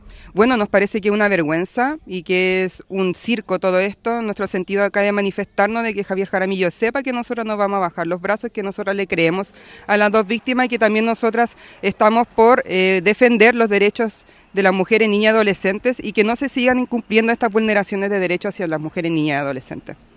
En tanto, a las afuera del liceo llegaron representantes de grupos feministas, quienes criticaron duramente con un megáfono la presencia del jefe comunal en la ceremonia.
cu-feministas-victoria.mp3